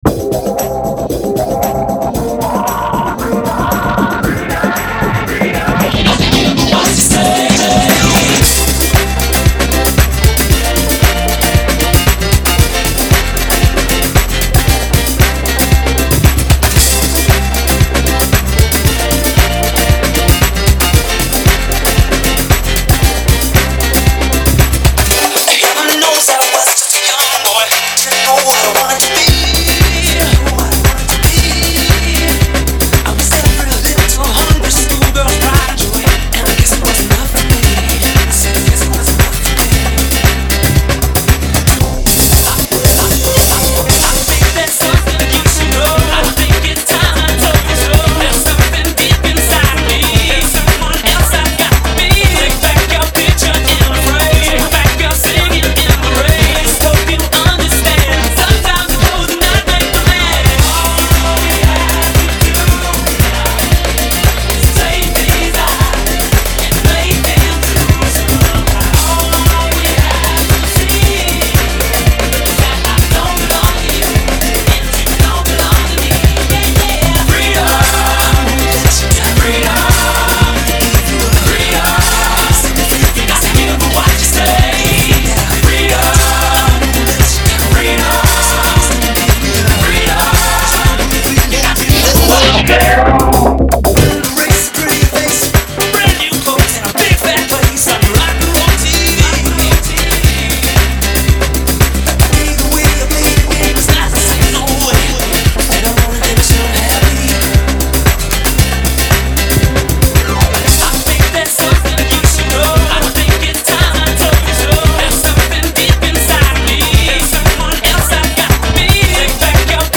House - 115bpm- 3:27.